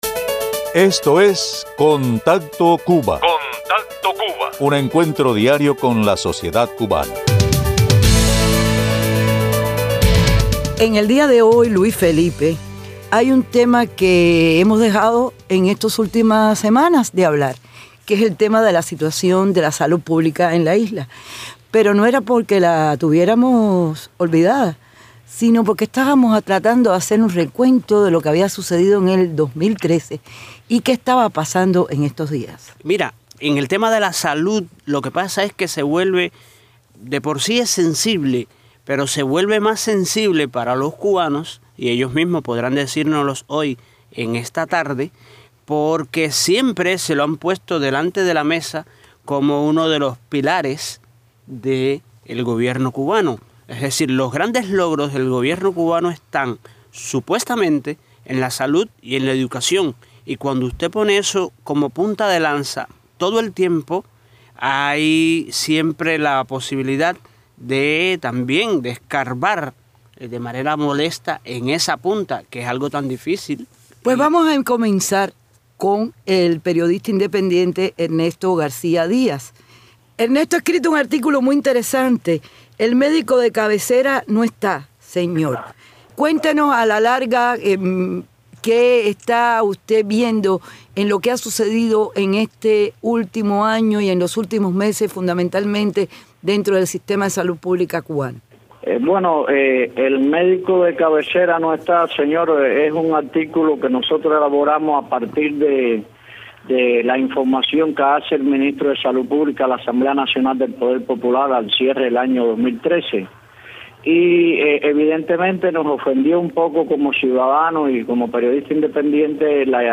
Varios ciudadanos presentan el testimonio de las condiciones atención de salud primaria, como el mito del Médico de familia.